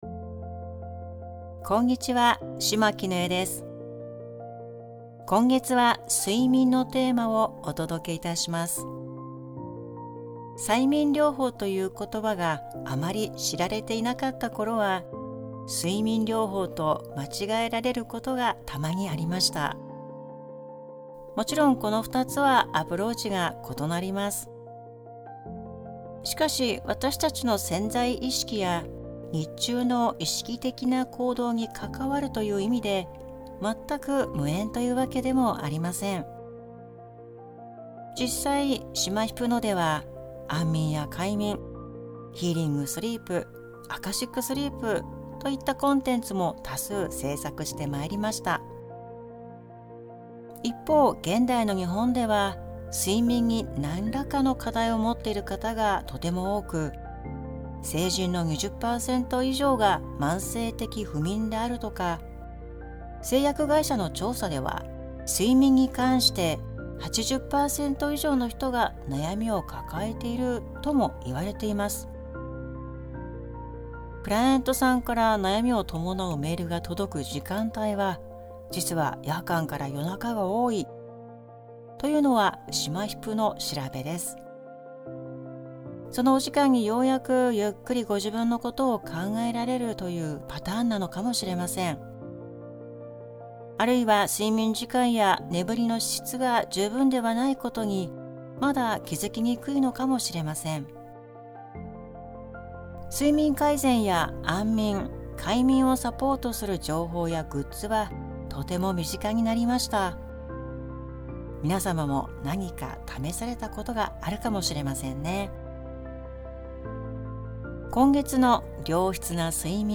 メッセージは、文字と音声でお届けしています。